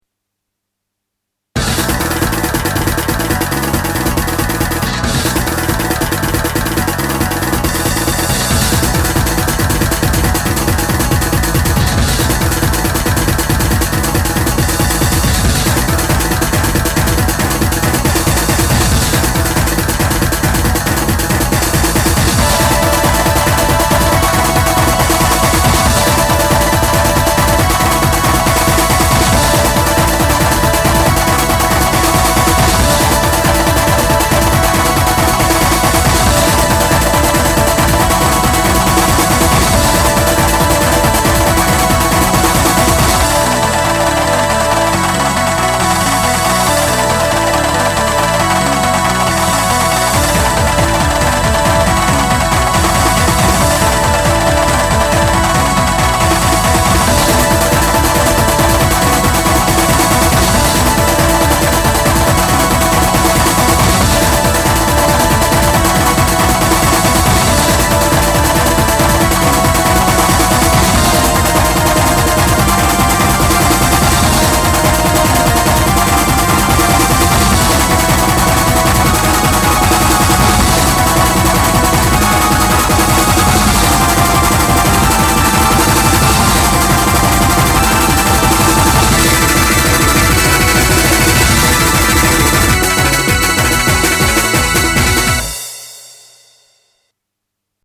Genre：drum'n'bass